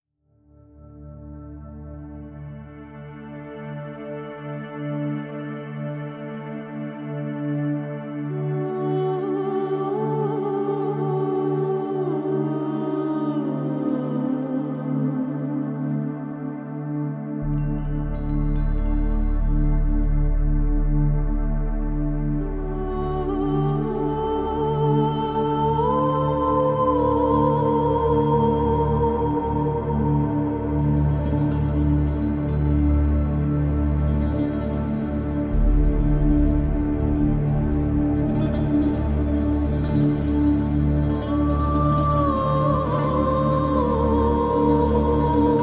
a cool, jazzy trip with a sci-fi chaser